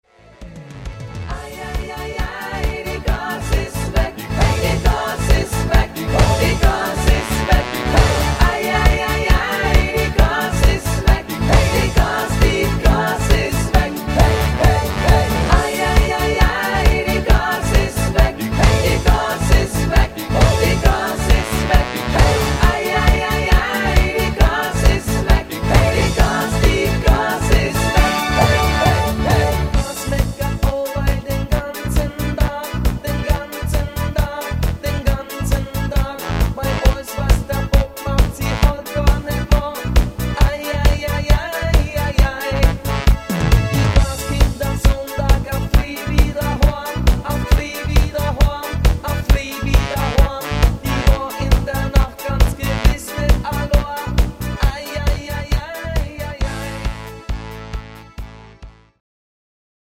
Stimmungshit